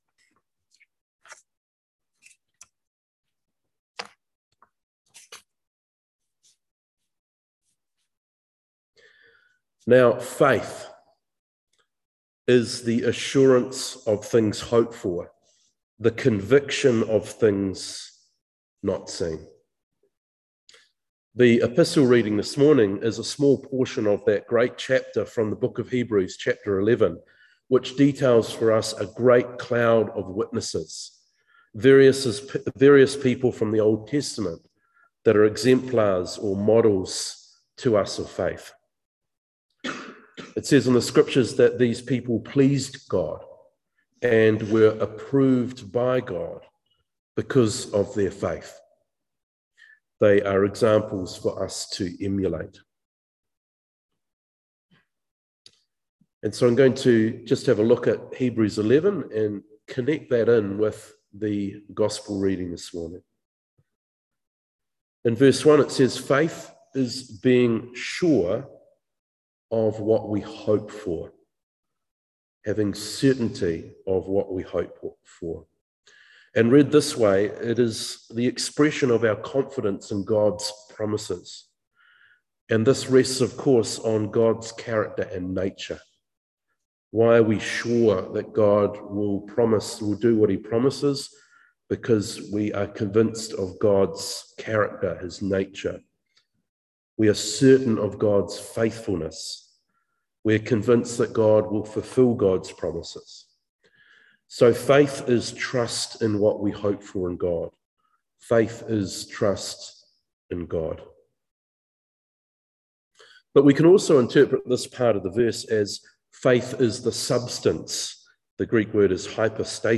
Luke 12:32-40 Service Type: Morning Worship Topics